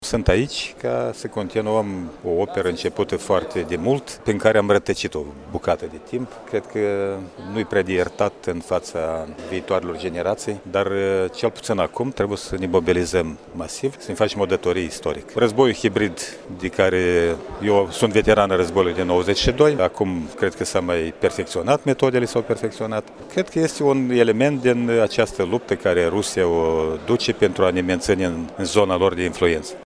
Astăzi, s-a constituit, la Iași, Liga Aleșilor Locali Unioniști.
Primarul localității Cimișlia, din Reublica Moldova, Gheorghe Răileanu, a spus că unirea reprezintă o datorie istorică și că Rusia încearcă să păstreze Republica Moldova în zona sa de influență: